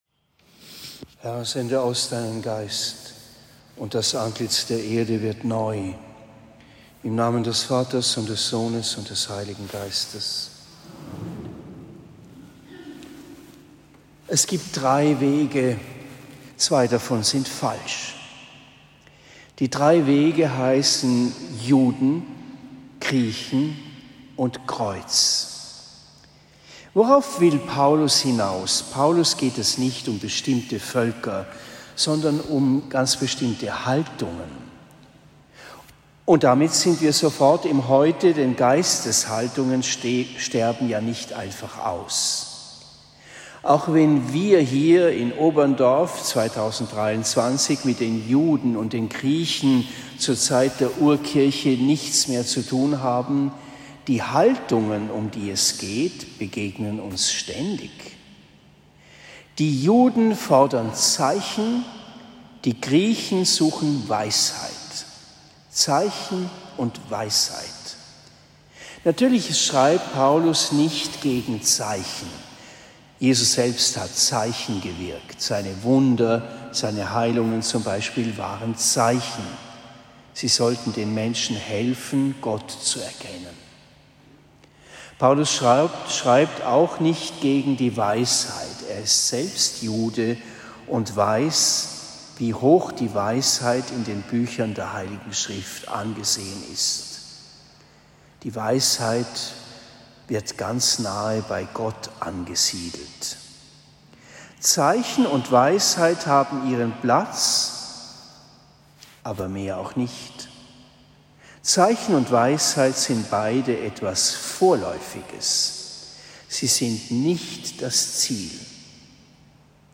Freitag der 21. Woche im Jahreskreis – (1 Kor 1,17-25) Predigt am 01. September 2023 in Oberndorf